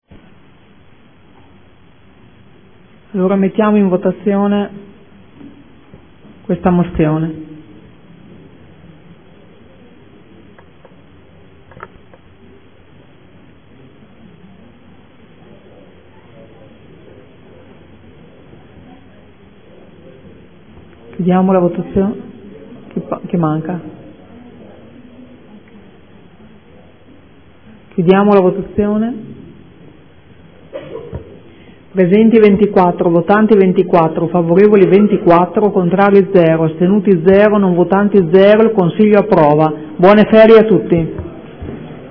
Seduta del 31/07/2014. Mettei ai voti Mozione 95423.